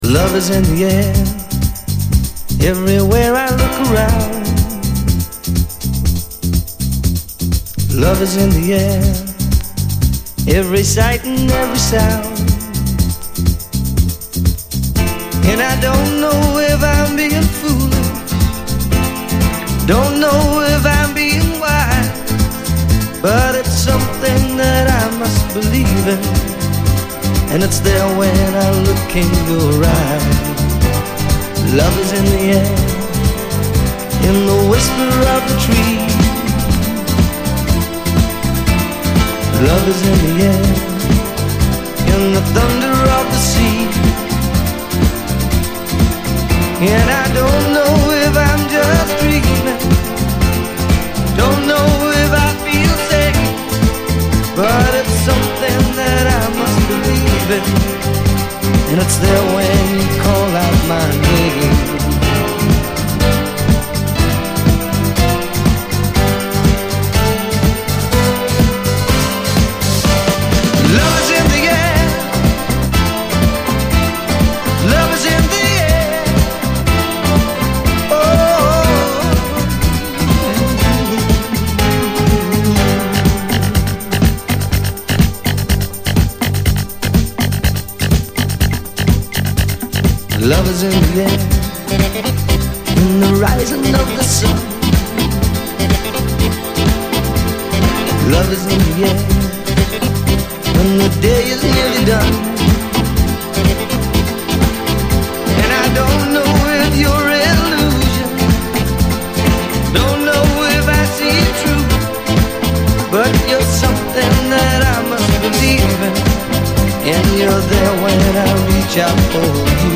盤見た目VG+ですがそれほどノイズは多くない。
USの男女混成３人組ブルーアイド・ソウル・グループ！